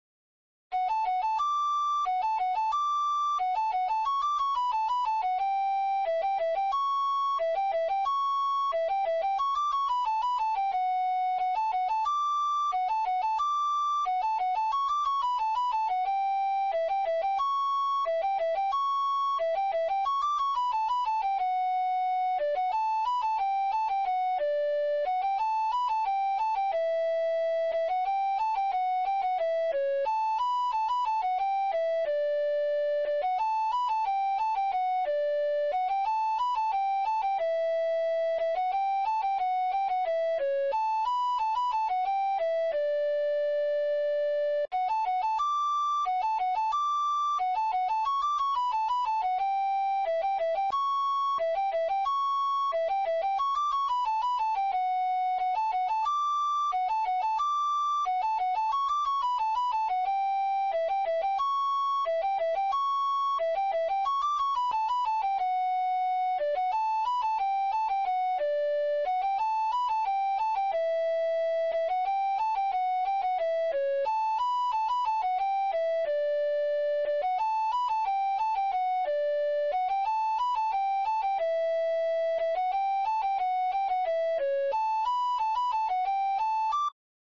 Pasacorredoiras – Pezas para Gaita Galega
Pasacorredoiras
Primeira voz